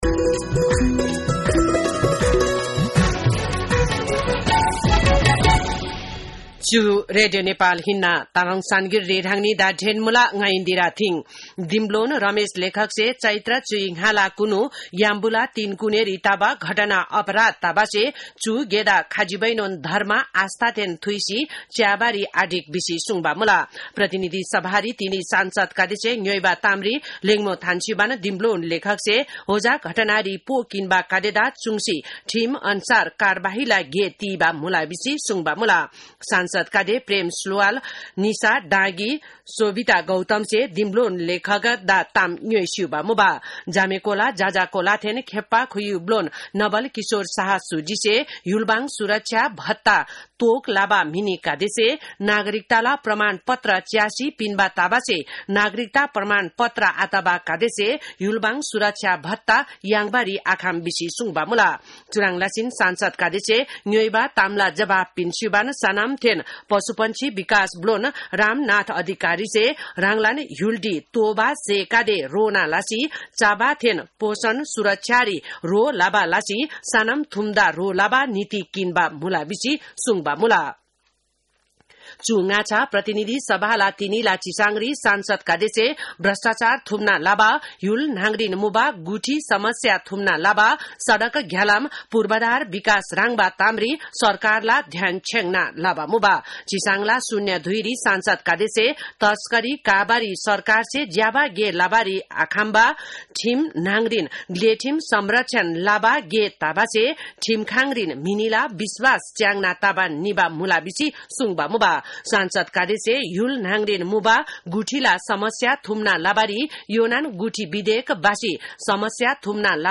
तामाङ भाषाको समाचार : ५ जेठ , २०८२
5.5-pm-tamang-news-.mp3